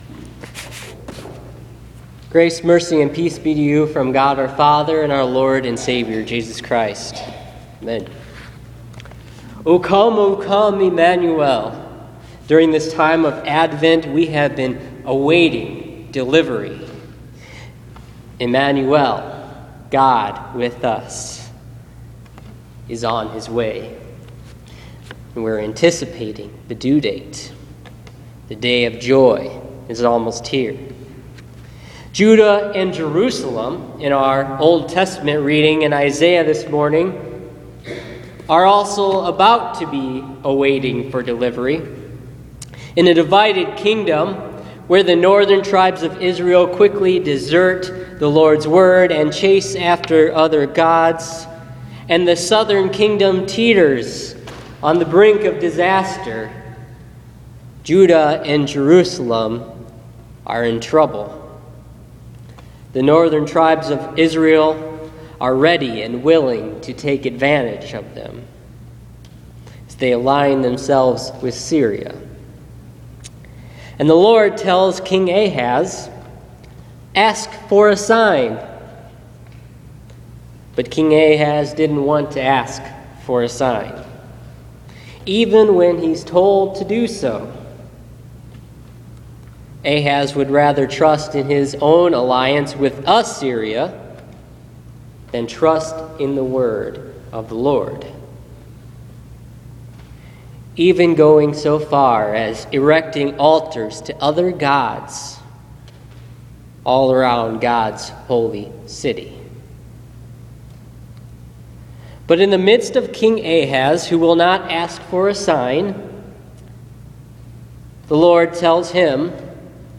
Listen to this week’s sermon on Isaiah 7:10-17 for Advent 4.